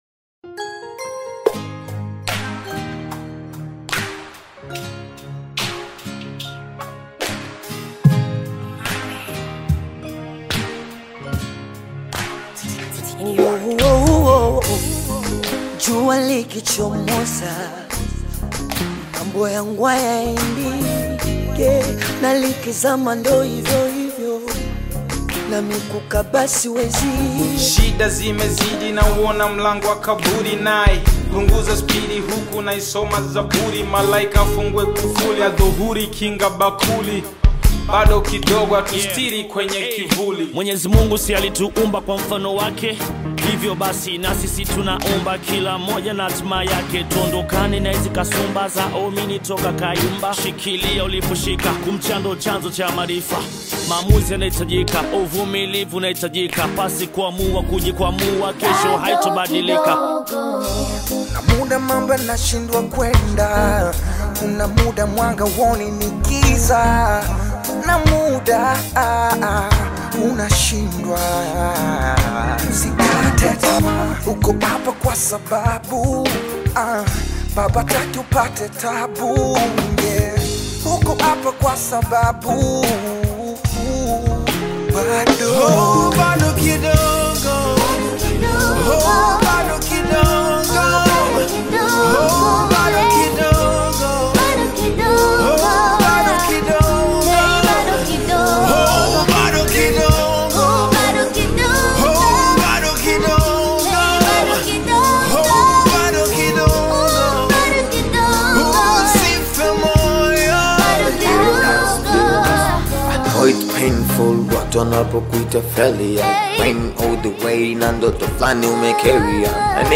BONGO